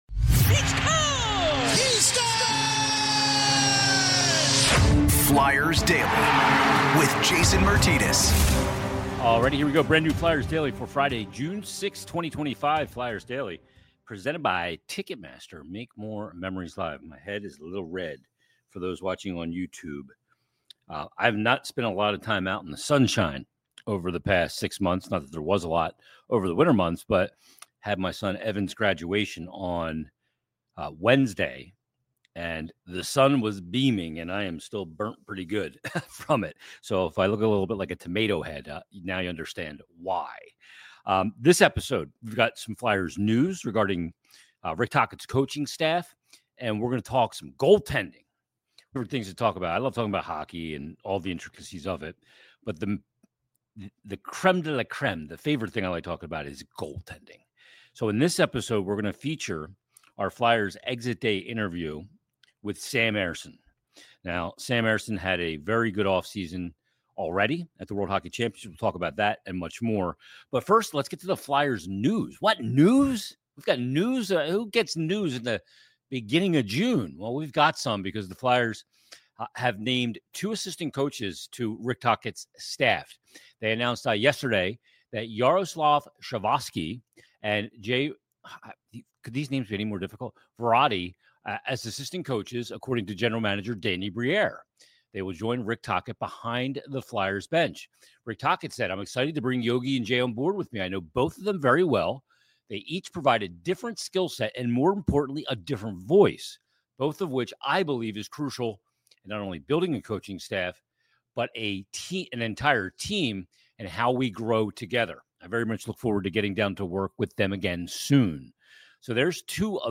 Plus our Flyers Exit Day Interview Series continues with Flyers Goaltender Sam Ersson. Sam discuss's his season on and off the ice, developing more as an NHL Goalie and a lot more.